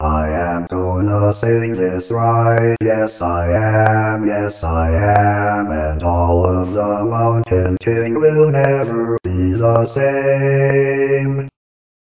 More MacinTalk fun.